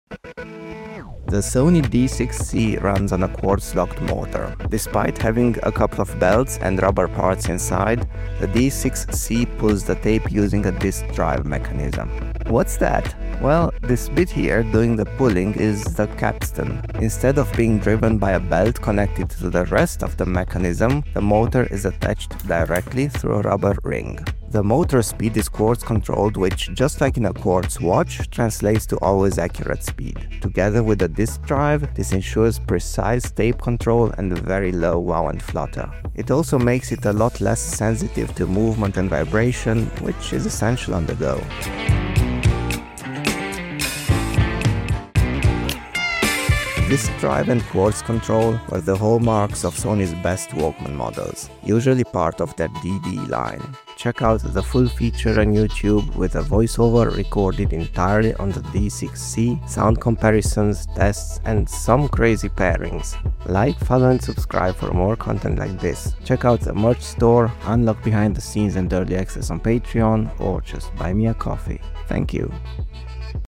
D6c reel The unique SONY DD (Disc Drive) mechanism as implemented in the Sony D6C professional Walkman.